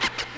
Southside fx's (8).wav